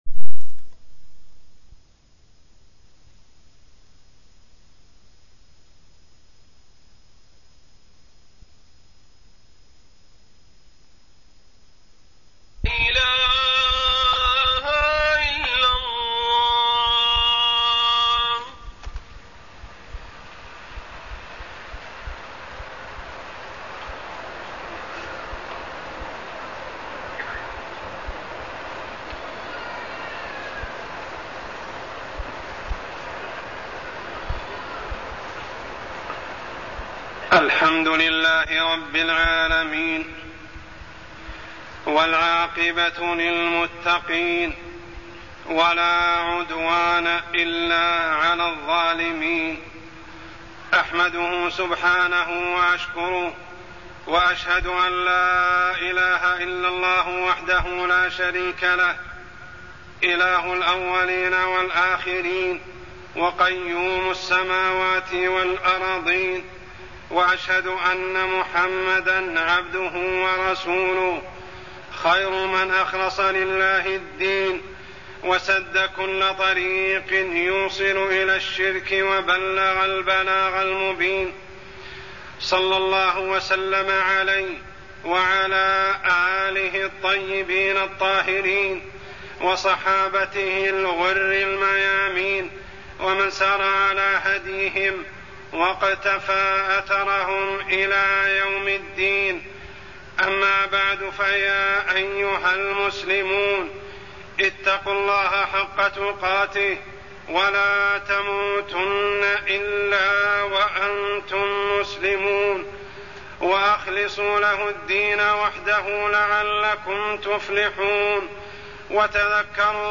تاريخ النشر ٢٤ جمادى الآخرة ١٤٢١ هـ المكان: المسجد الحرام الشيخ: عمر السبيل عمر السبيل كلمة التوحيد